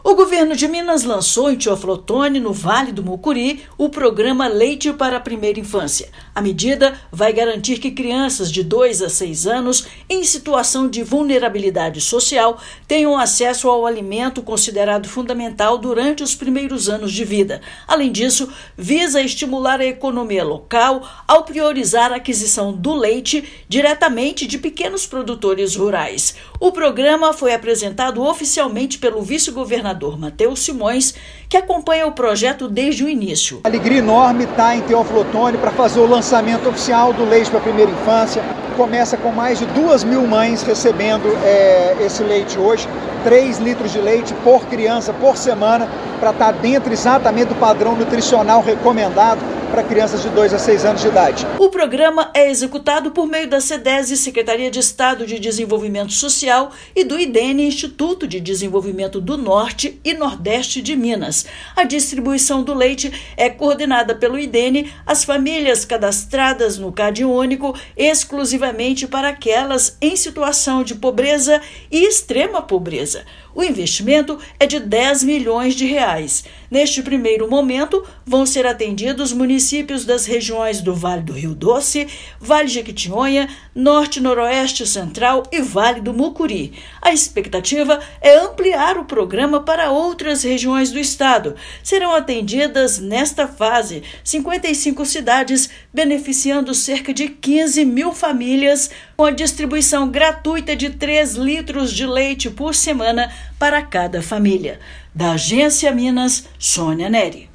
Iniciativa apresentada oficialmente pelo vice-governador vai beneficiar 15 mil famílias em vulnerabilidade com distribuição gratuita do alimento. Ouça matéria de rádio.